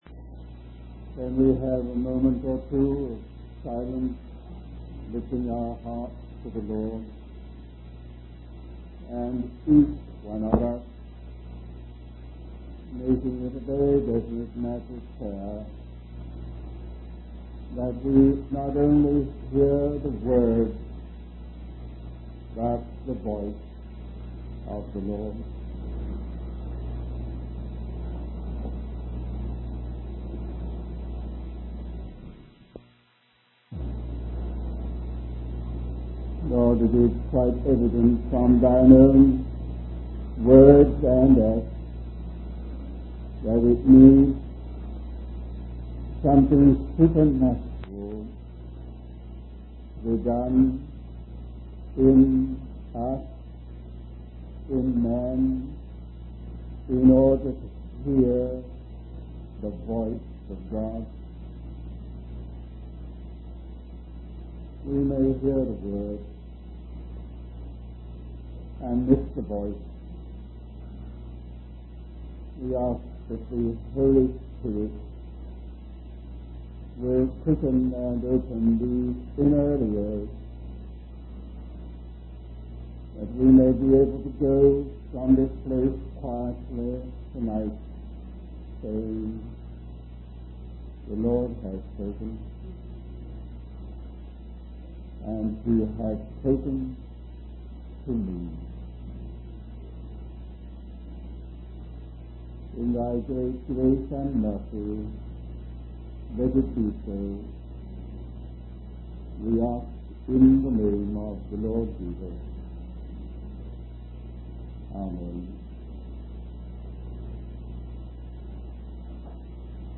In this sermon, the speaker emphasizes the importance of understanding the great thought of God's plan for humanity.